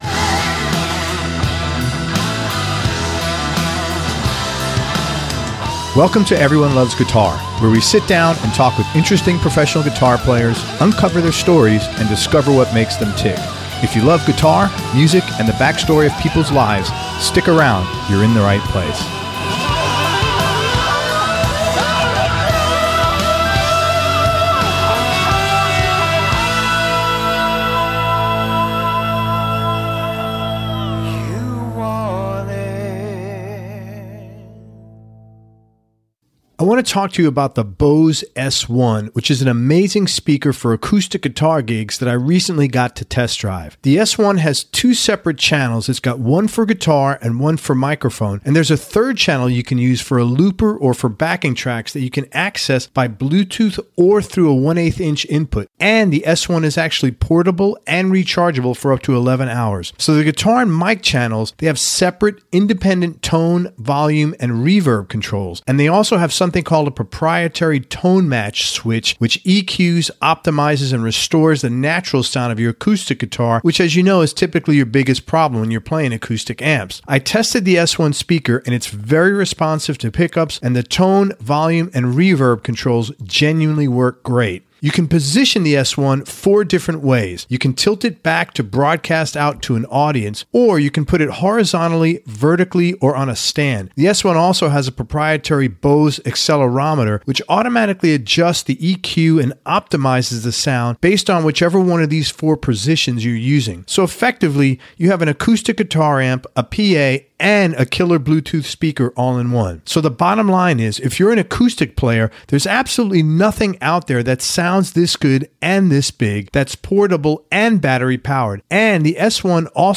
(captured from a web cast)